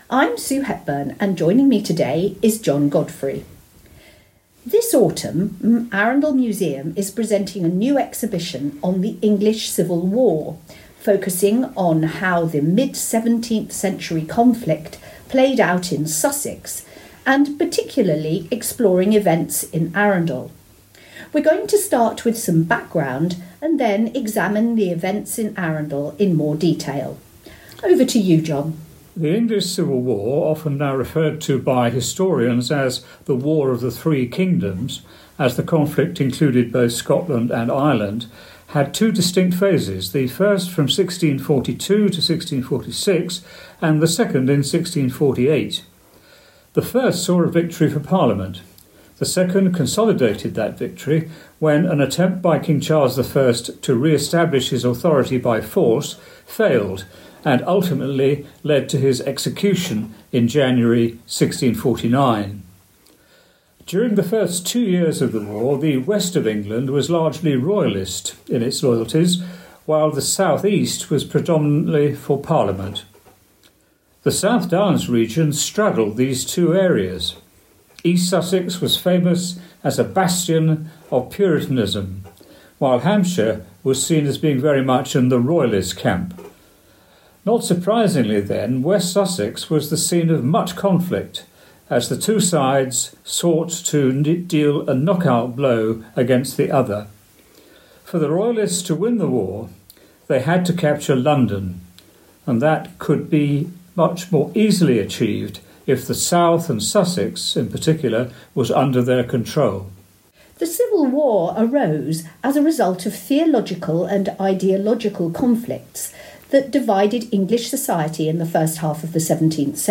Enjoy listening to this recording , made for the South Coast Talking Newspaper, which gives an audio version of the Civil War exhibition.